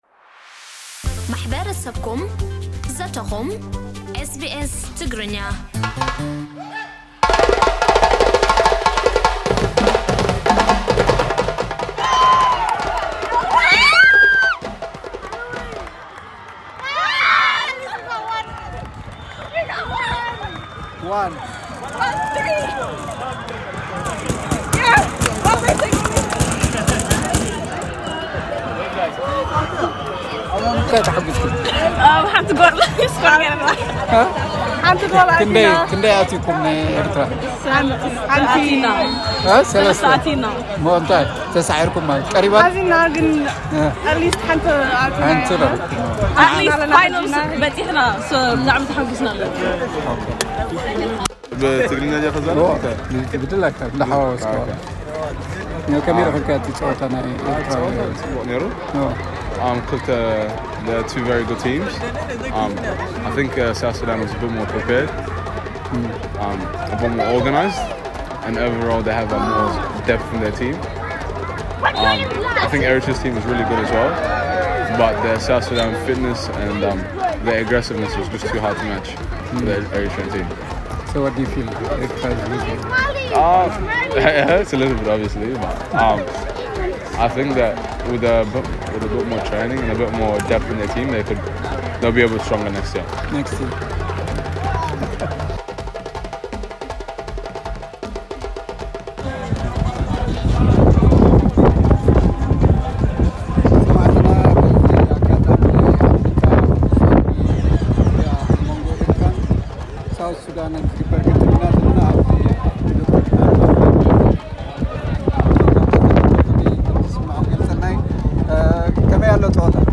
ኣብ መወዳእታ ጋንታ ደቡብ ሱዳን ንጋንታ ኤርትራ 3 ብ 1 ብምስዓር ናይ'ዚ ዓመት ዋንጫ ዓቲራ። ኣብ'ቲ ብዉዕዉዕ ኣፍሪቃዊ ሙዚቃ ተሰንዩ መንእሰያት እናሳዕስዑ ዝተዓዘብዎ ጸወታ ተረኺብና ንገለ ኣብ'ቲ ቦታ ዝነበሩ ኣዛሪብና ኣለና።